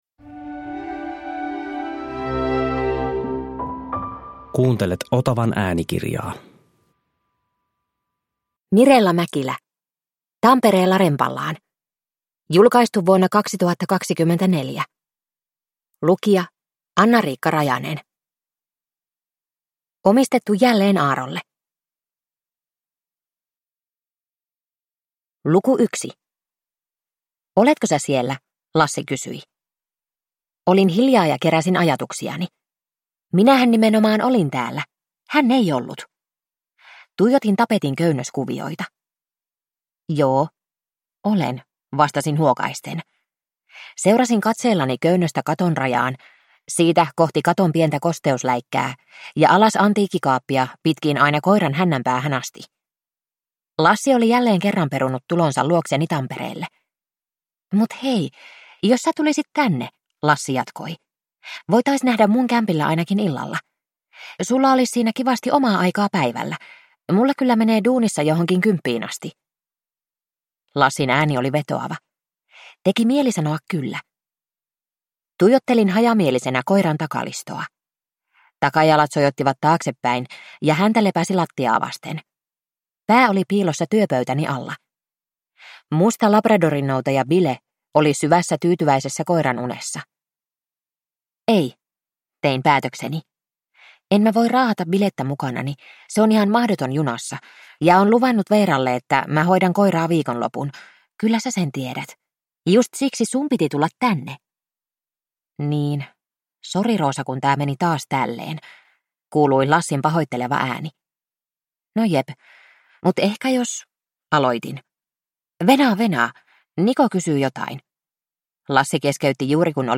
Tampereella rempallaan – Ljudbok